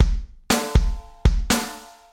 ここではドラムのVSTiでシンプルなリズムを打ち込んでみました。
最初に打ち込んだ音をRenderしたフレーズ・サンプル（MP3）